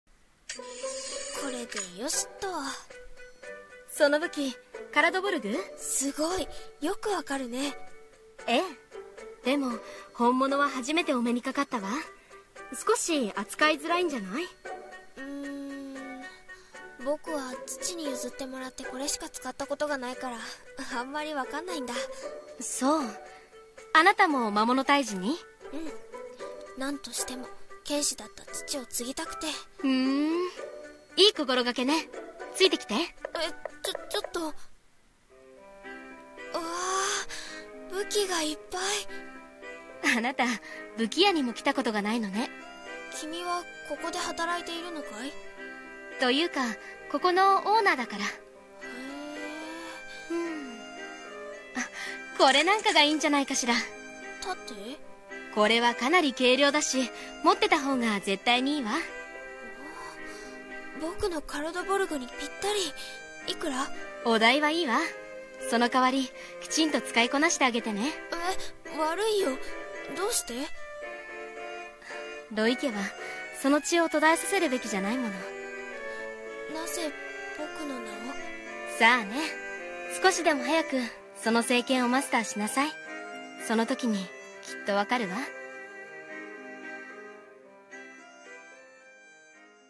【コラボ声劇】剣に選ばれし者